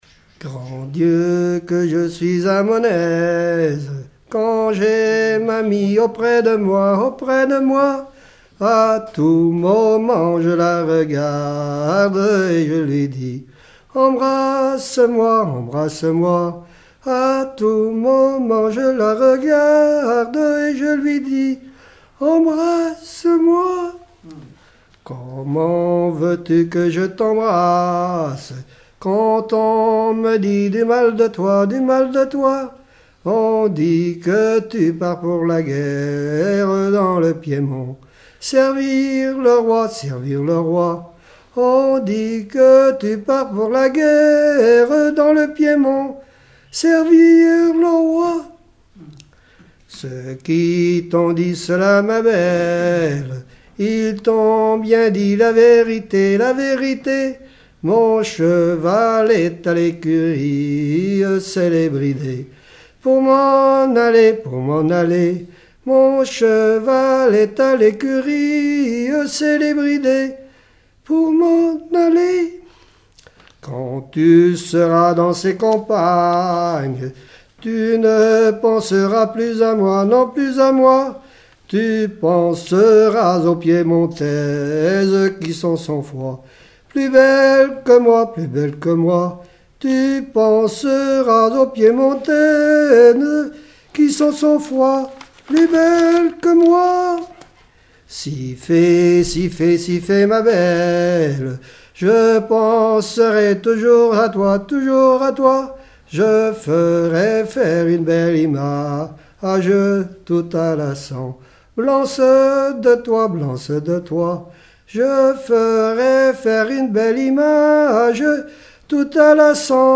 Mémoires et Patrimoines vivants - RaddO est une base de données d'archives iconographiques et sonores.
Genre dialogue
collectif de chanteurs du canton
Pièce musicale inédite